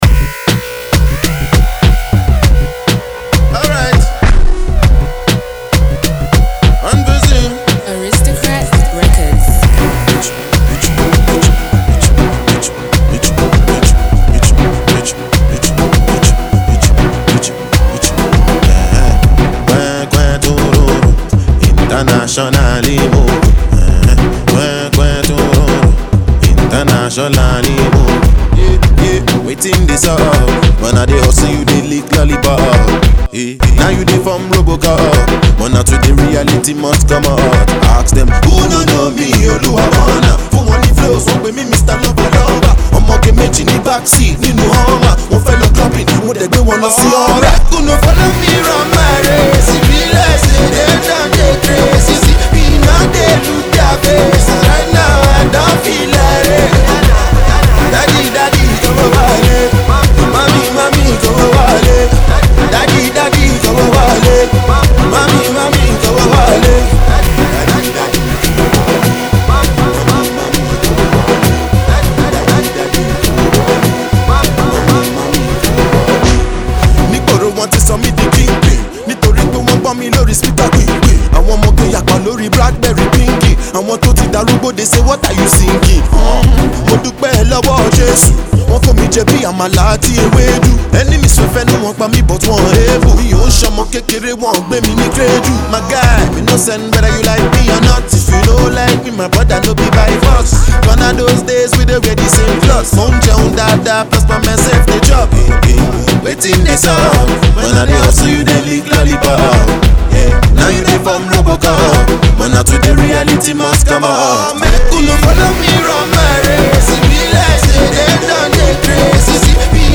the hefty beat-laced, afrobeats-inspired